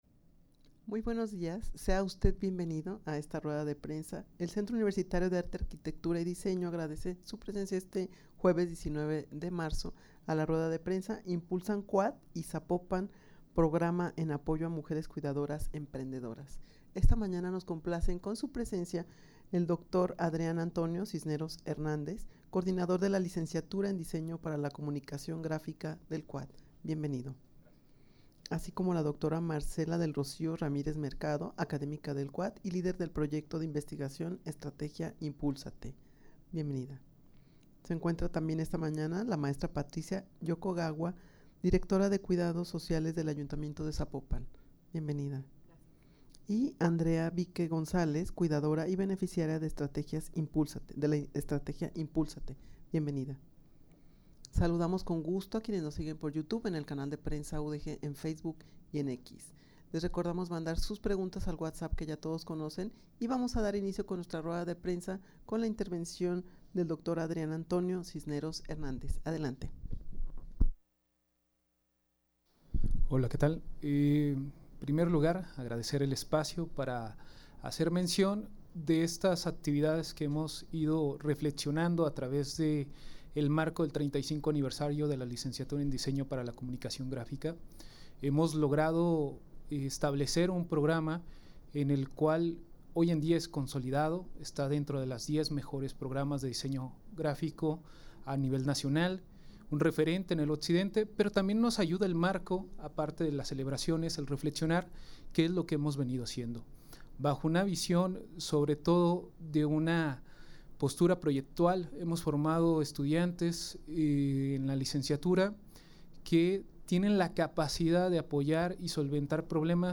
Audio de la Rueda de Prensa
rueda-de-prensa-impulsan-cuaad-y-zapopan-programa-en-apoyo-a-mujeres-cuidadoras-emprendedoras.mp3